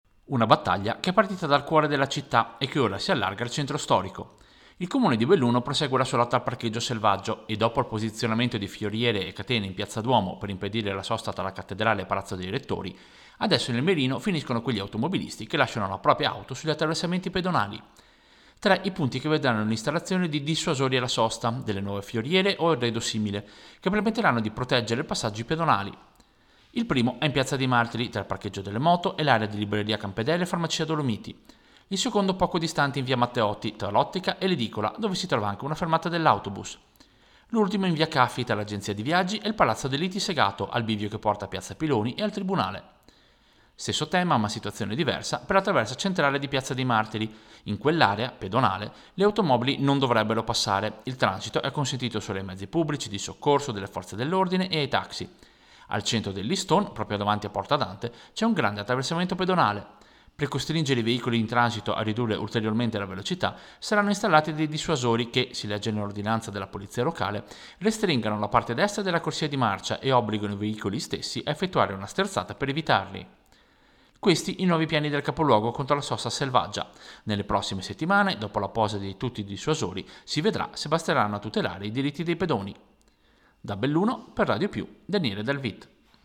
Servizio-Dissuasori-sosta-centro-Belluno-1.mp3